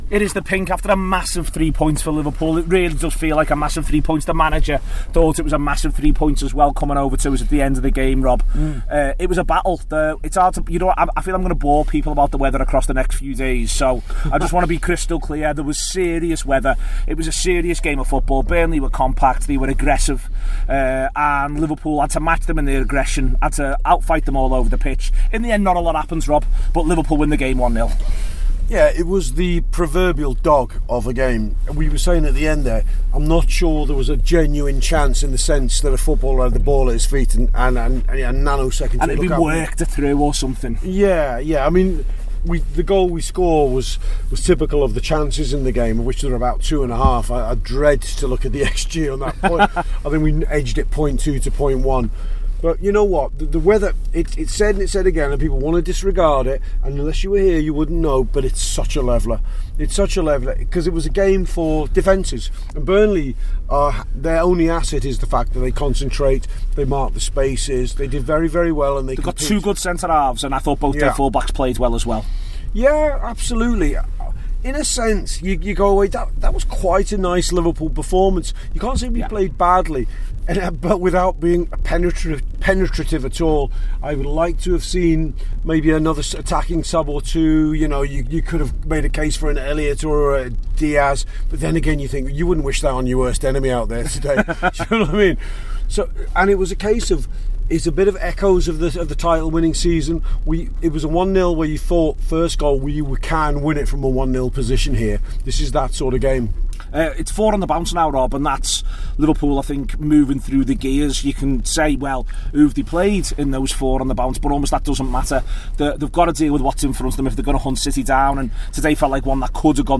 The Anfield Wrap’s post-match reaction podcast after Burnley 0 Liverpool 1 in the Premier League at Turf Moor.